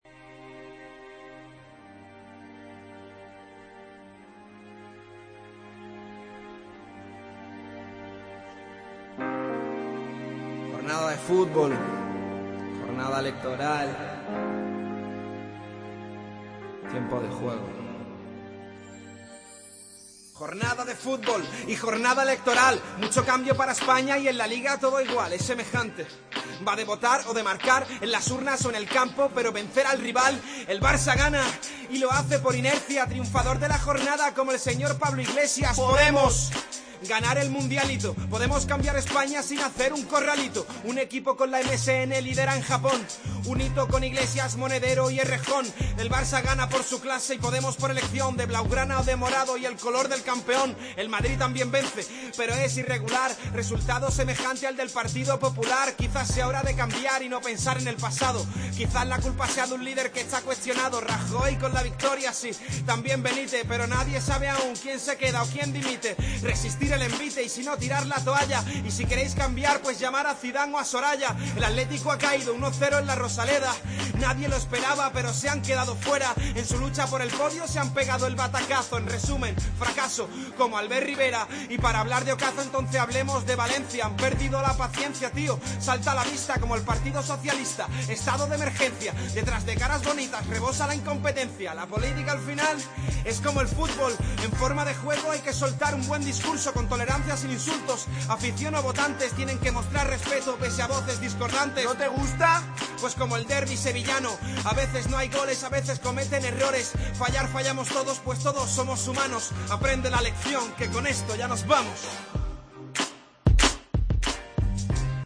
Tiempo de Juego a ritmo de rap